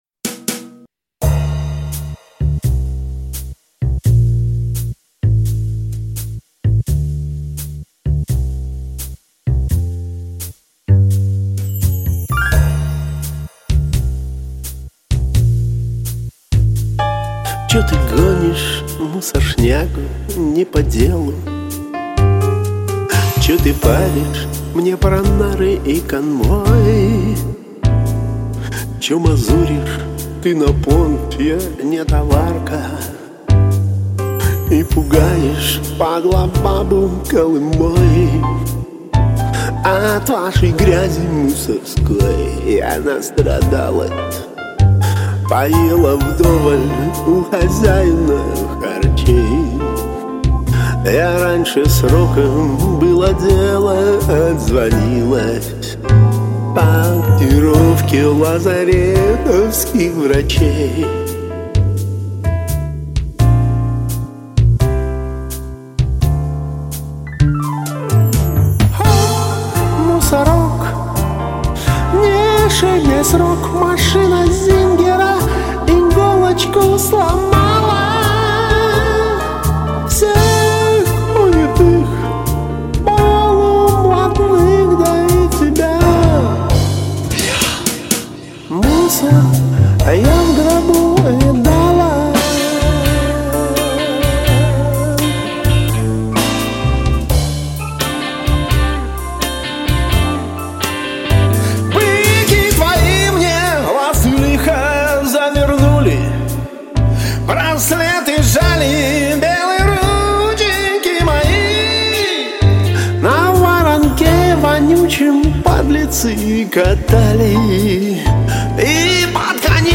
просто баллада джазово/зарубежная с оперными элементами